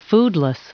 Prononciation du mot foodless en anglais (fichier audio)